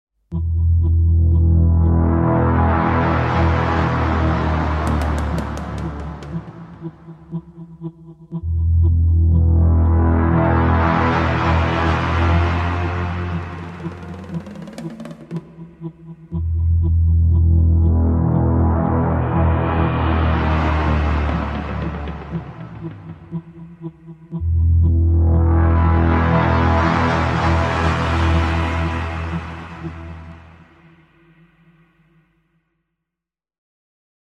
Largo [0-10] suspense - ensemble instruments - - -